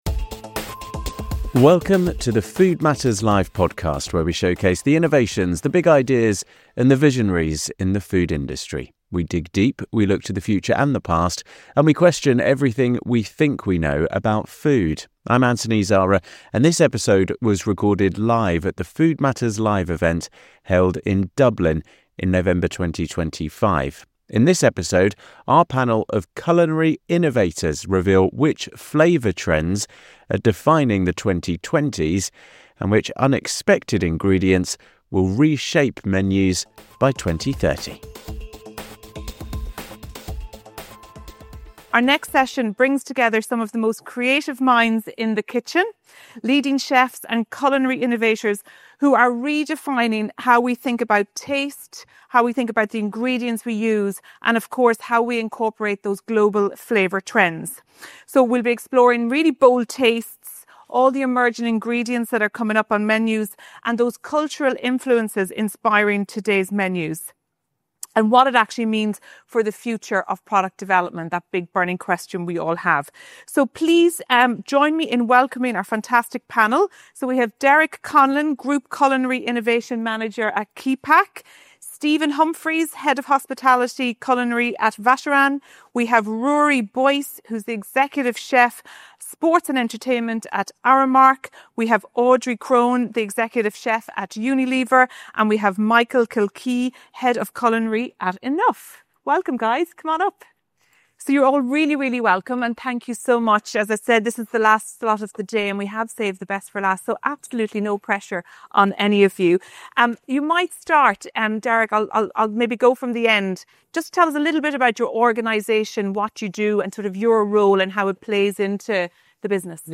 In this episode of the Food Matters Live podcast, recorded at our event in Dublin, five culinary innovators reveal which flavour trends are defining the 2020s - and which unexpected ingredients will reshape menus by 2030. The panel dissects everything from "borderless cuisine", to the relentless march of hot honey across every category.